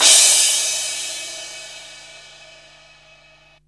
CRASH04   -L.wav